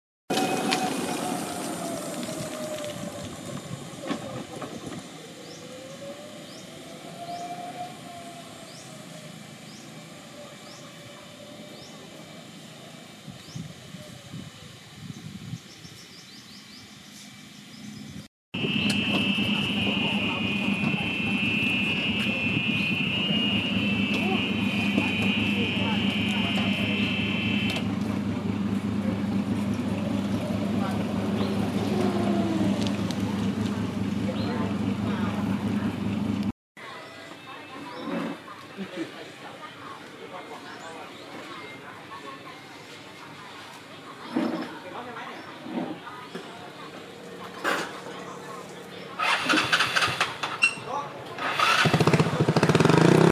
เสียงบรรยากาศ